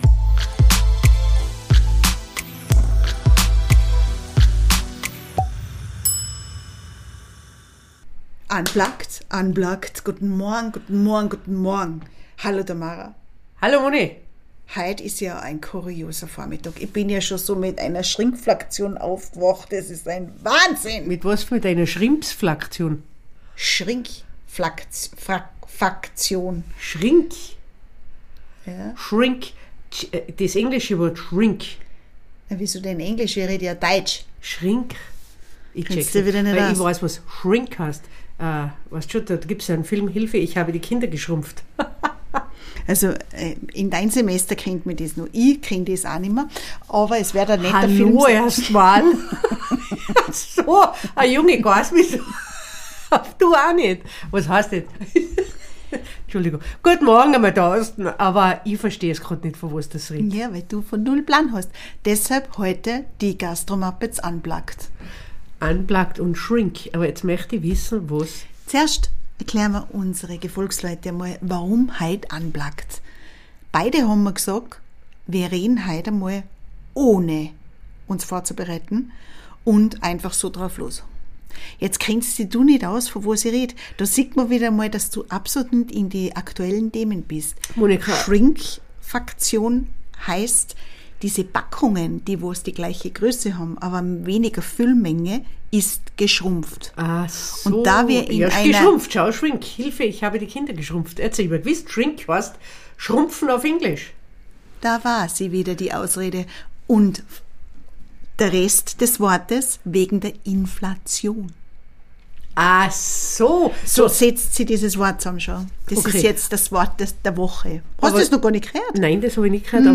Heute mal unplugged... wir haben auch so soviel zu quatschen oder zu bereden. Die Tage werden kürzer der Duft von Schnee ist auch schon in der Luft und was es so neues gibt erfahrt ihr hier und heute.